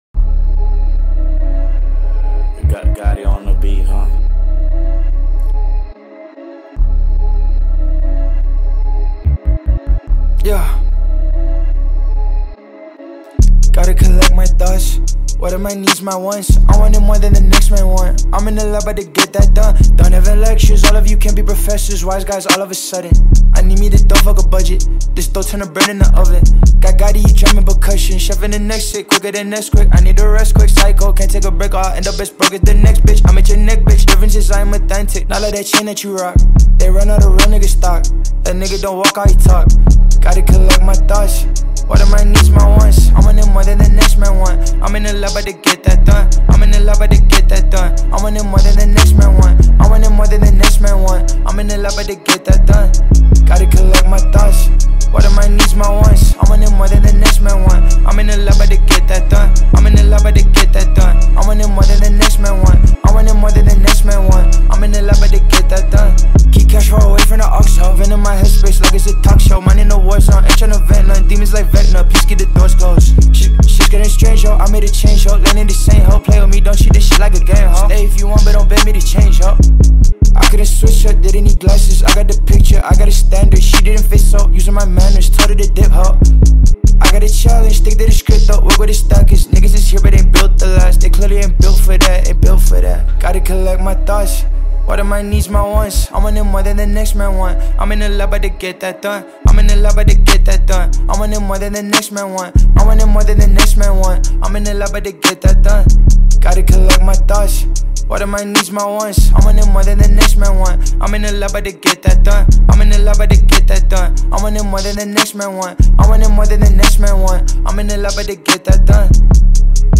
A hip-hop song